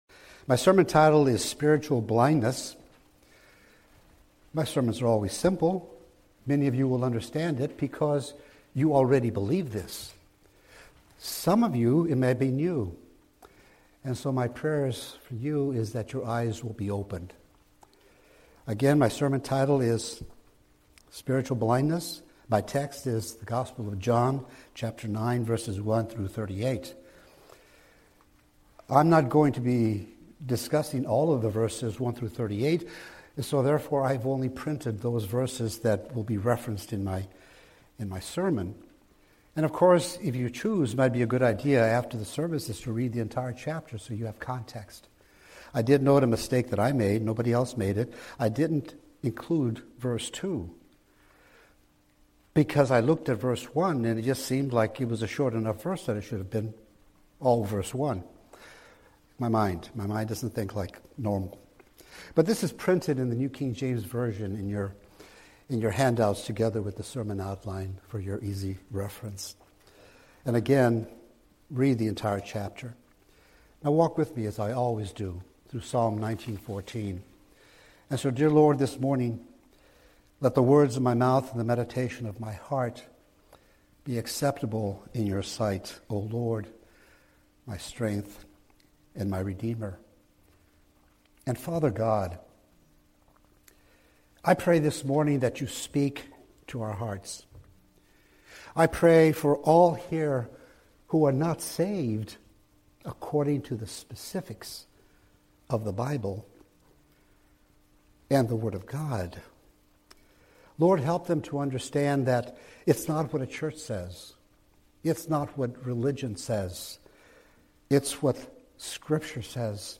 Weekly sermons from New Hope Chapel, Carolina, RI., an evangelical community church.